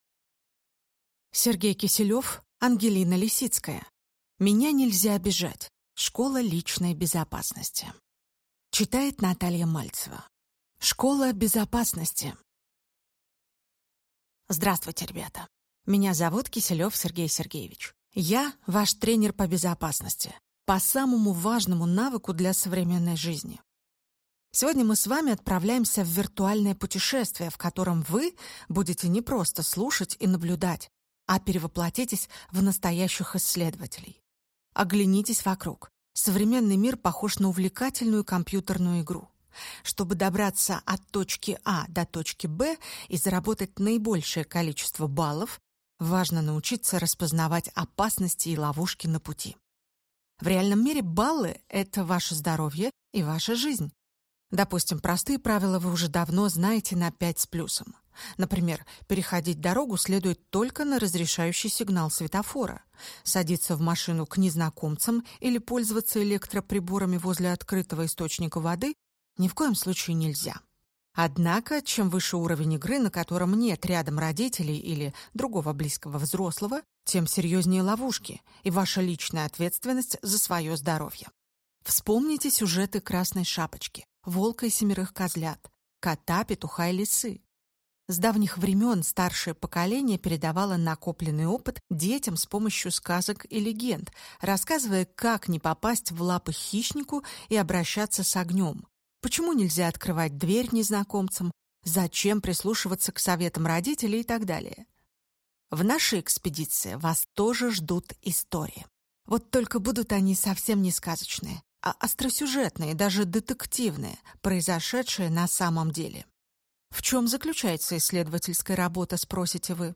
Аудиокнига Меня нельзя обижать! Школа личной безопасности | Библиотека аудиокниг